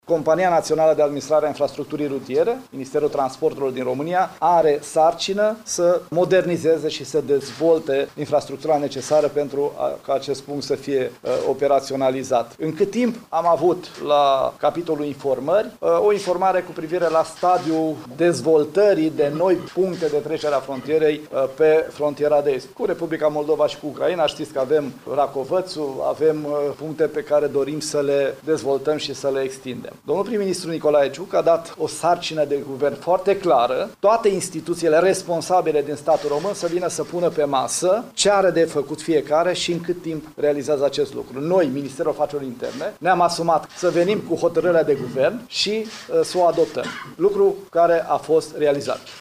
Măsura va permite fluidizarea traficului la frontiera dintre România și Republica Moldova și reducerea timpului de așteptare la celelalte puncte de trecere a frontierei de stat, care acum sunt mai solicitate, a declarat ministrul afacerilor interne, Lucian Bode.